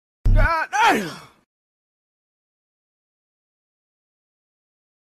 God Damn Meme Sound Effect sound effects free download